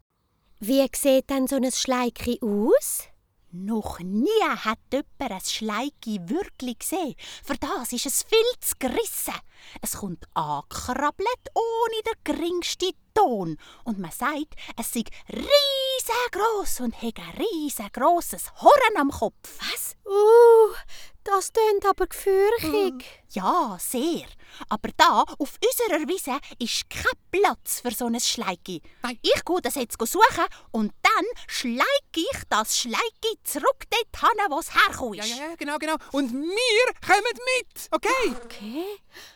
Dialekt Hörspiel